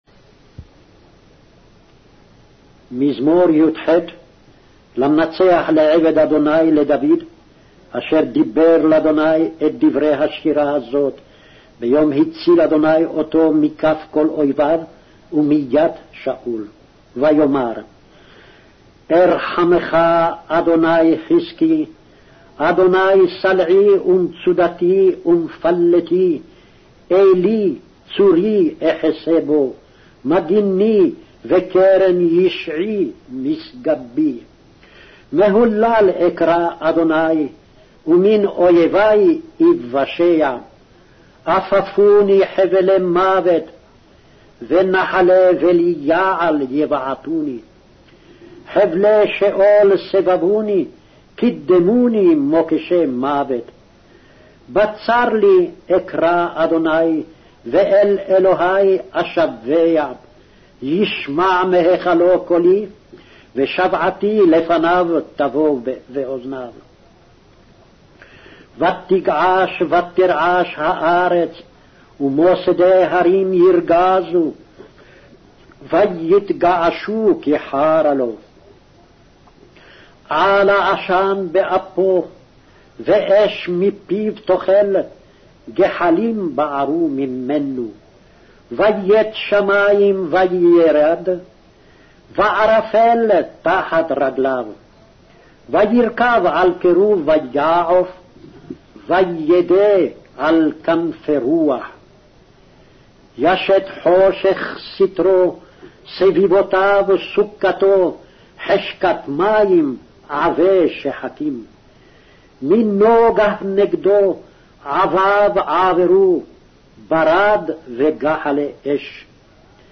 Hebrew Audio Bible - Psalms 51 in Ecta bible version